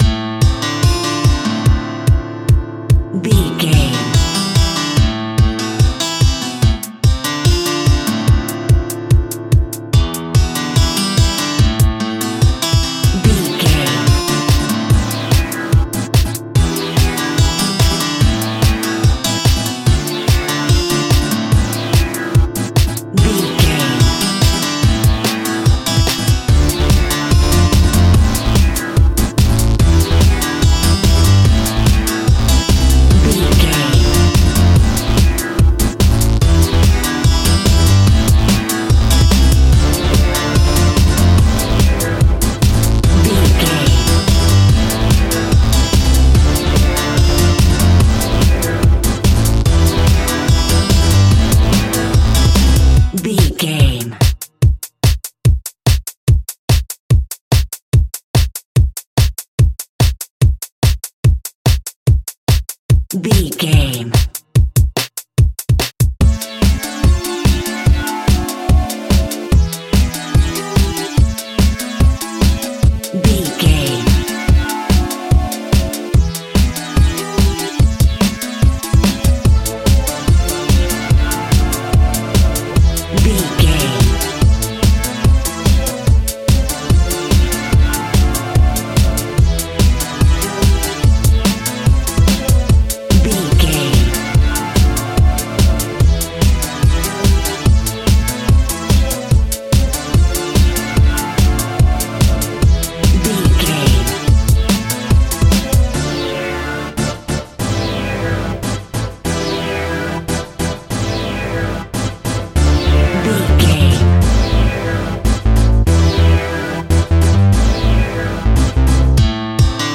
Aeolian/Minor
Fast
driving
energetic
futuristic
hypnotic
industrial
drum machine
acoustic guitar
synthesiser
acid house
electronic
uptempo
synth leads
synth bass